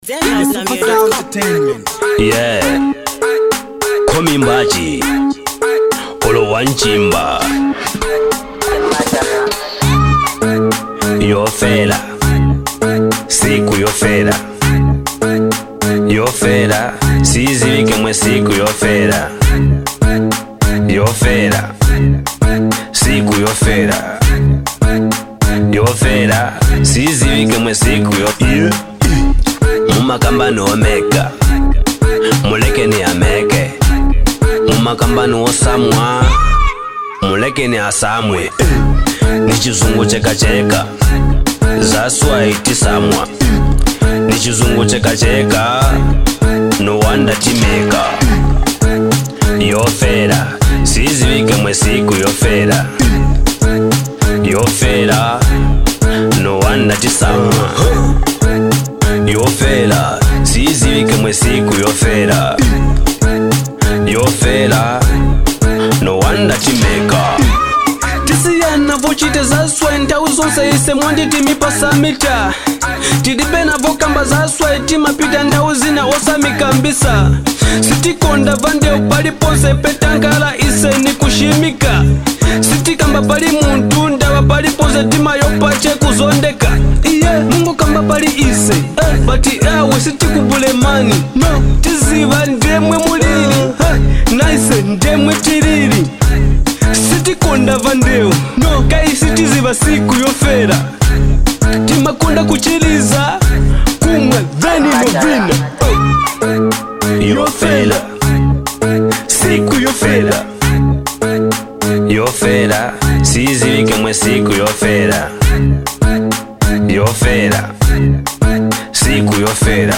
hard-hitting production